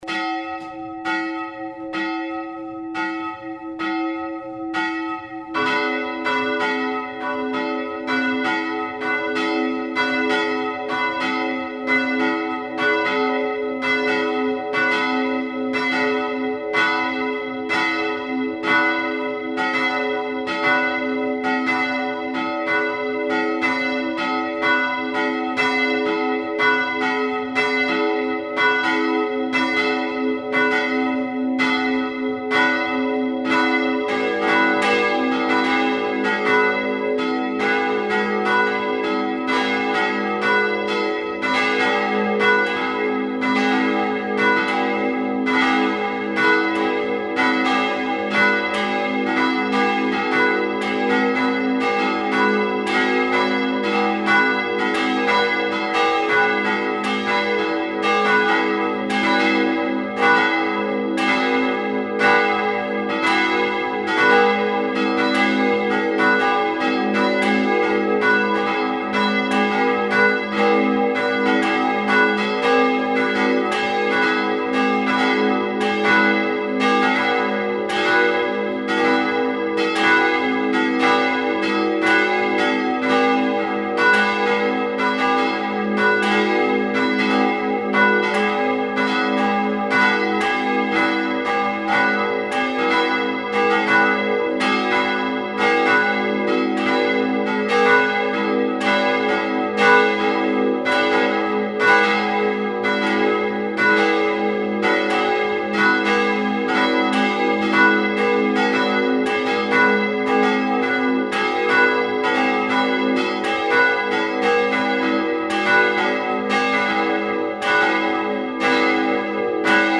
Die Glocken stammen aus den Jahren 1310, 1380 und 1432.
Das Geläut
Geläut Kirchberg